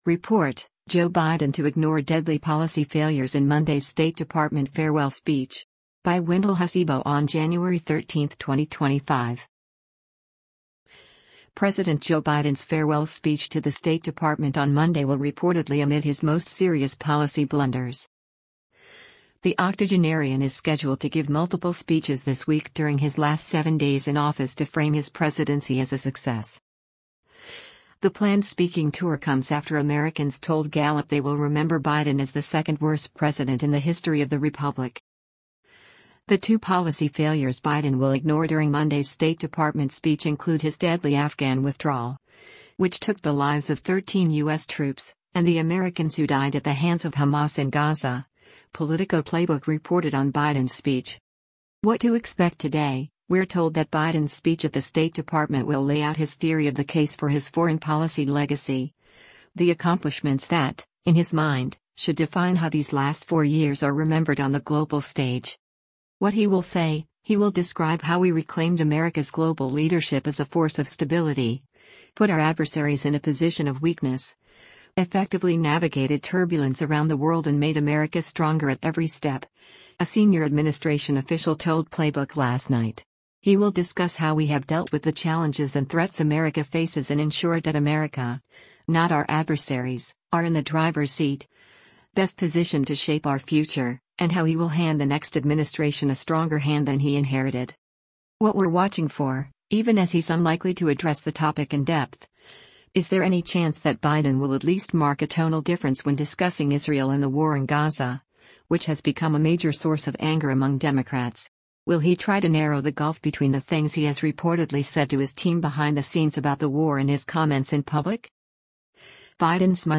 President Joe Biden delivers remarks on the overthrow and collapse of the Syrian governmen